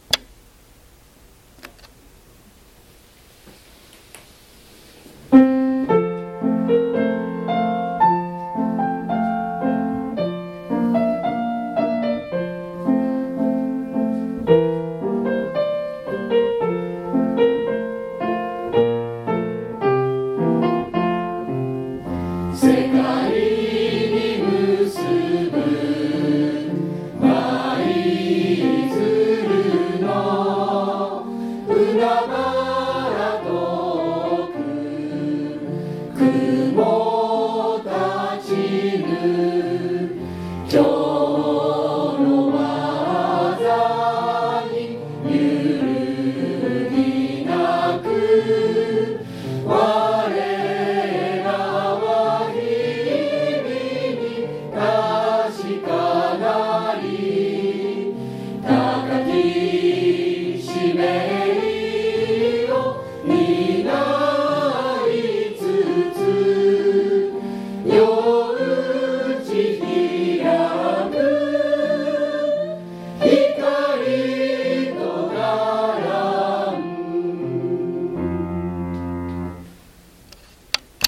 17日の卒業式に向けて、教職員で校歌の録音を行いました。
校歌（R５.3.1version） （ぜひ、お聞きください。）